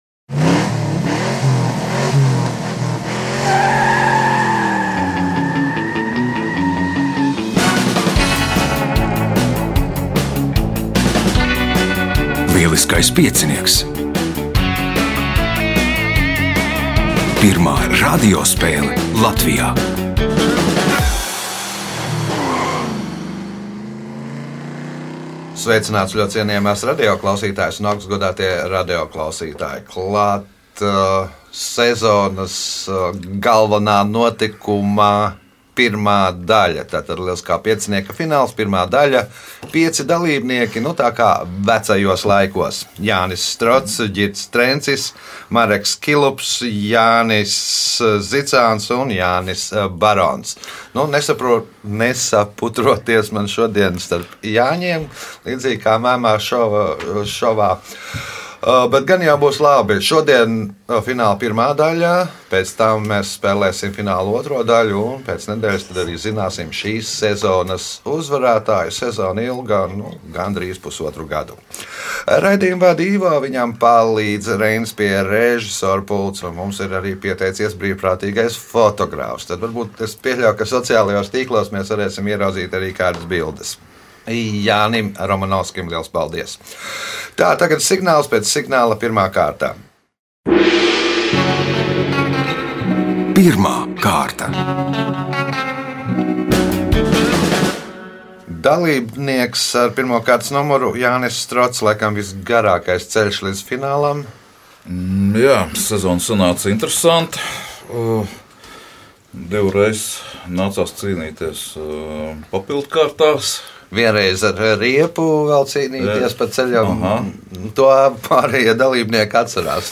"Lieliskais piecinieks" ir pirmā izklaidējošā radiospēle Latvijā.